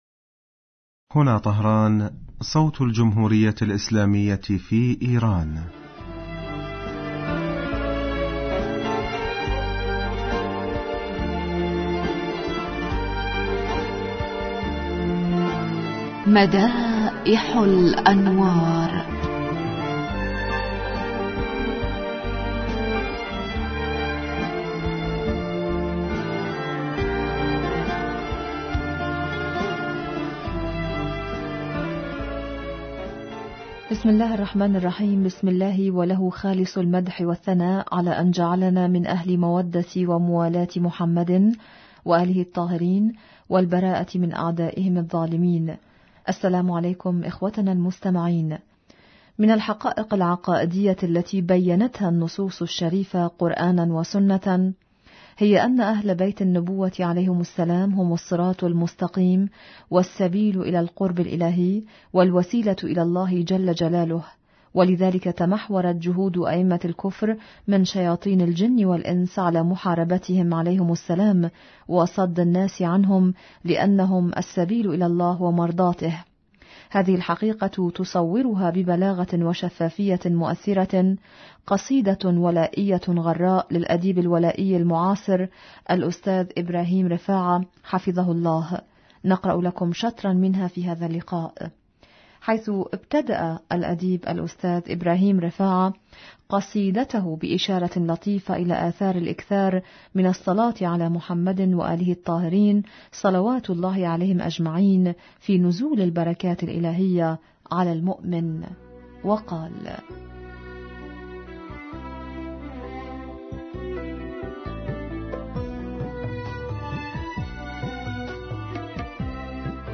إذاعة طهران- مدائح الانوار: الحلقة 331